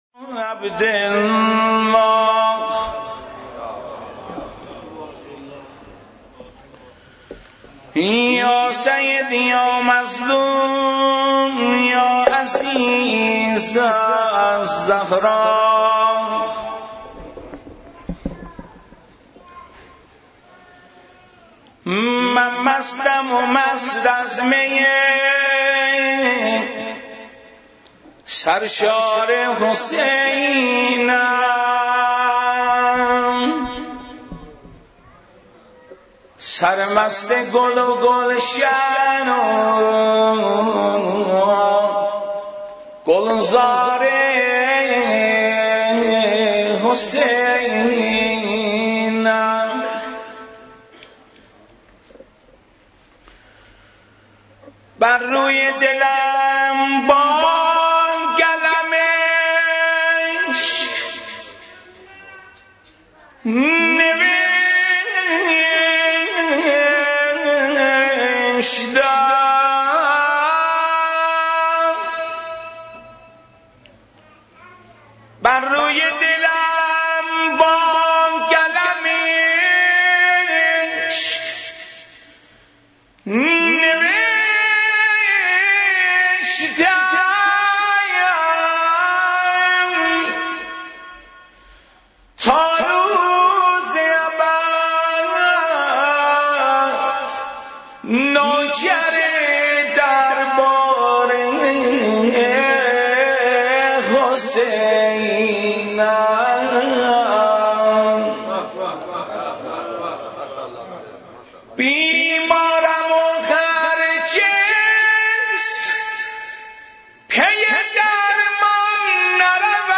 نوحه ترکی،آذری